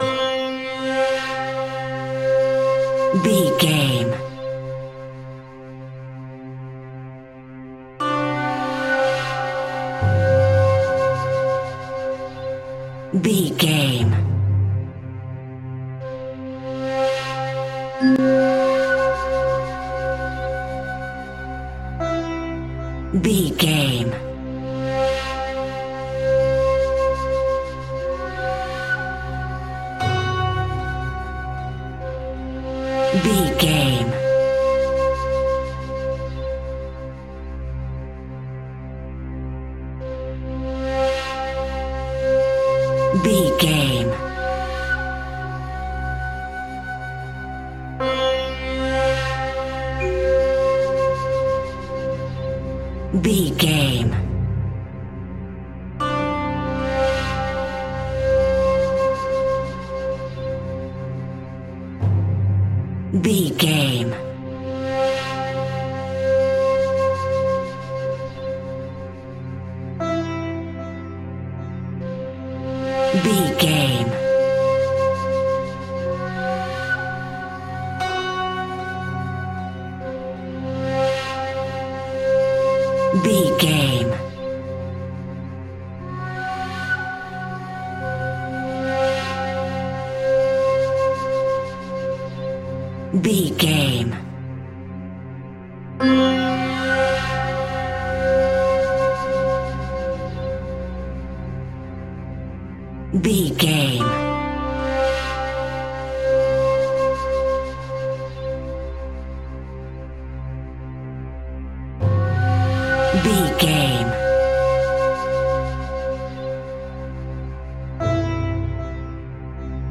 Atonal
Slow
suspense
tension
World Music
percussion